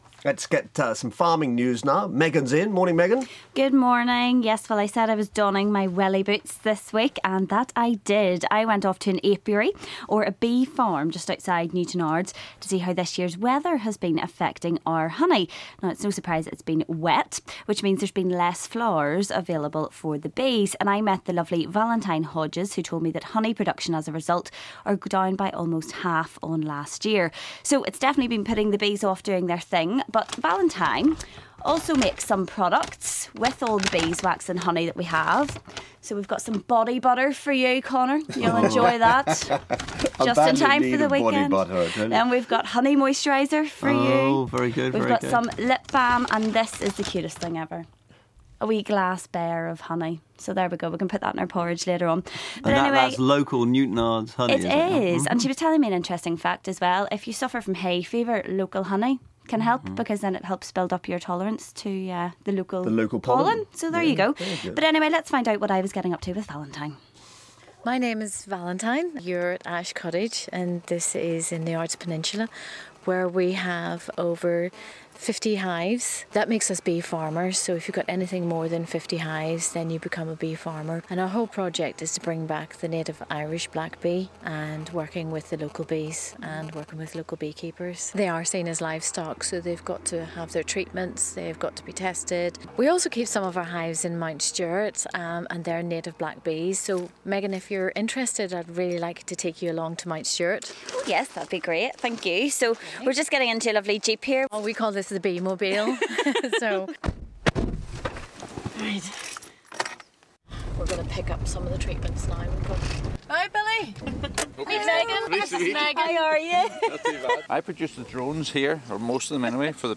I was off to an Apiary (bee farm) outside Newtownards to see how this year's weather has been affecting our honey.